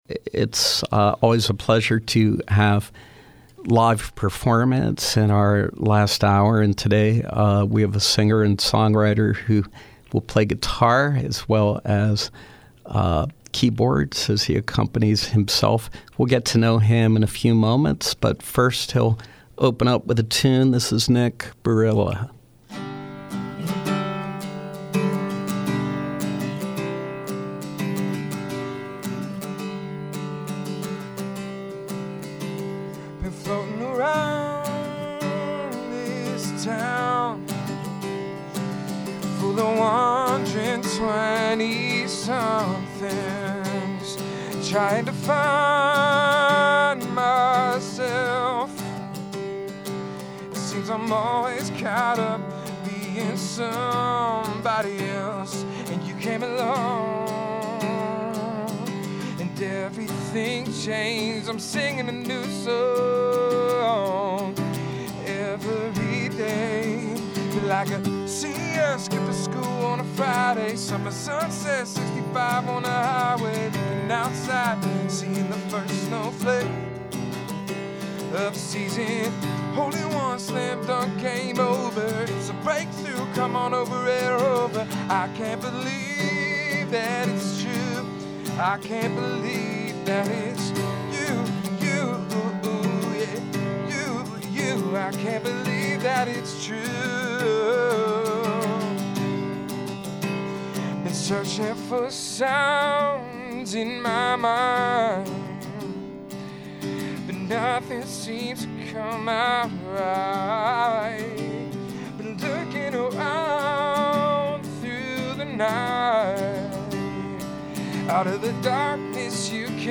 Live music with singer/songwriter